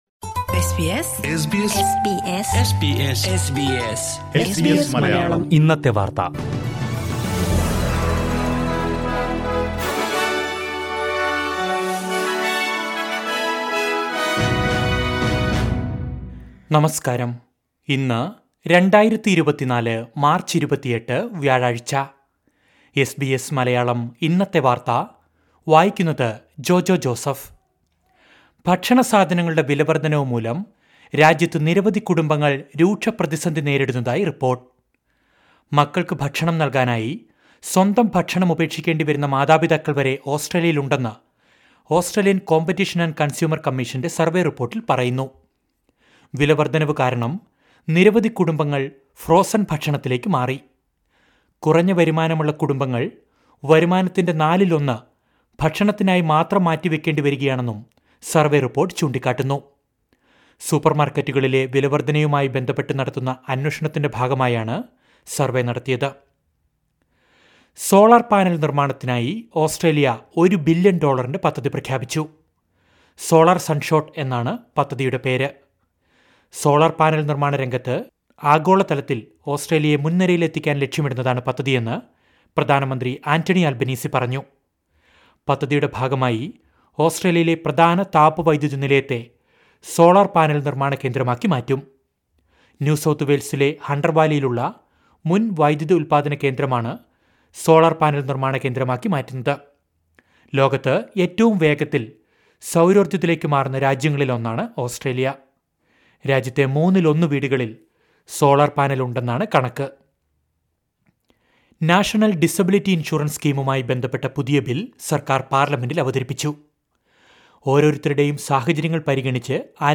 2024 മാര്‍ച്ച് 28ലെ ഓസ്‌ട്രേലിയയിലെ ഏറ്റവും പ്രധാന വാര്‍ത്തകള്‍ കേള്‍ക്കാം...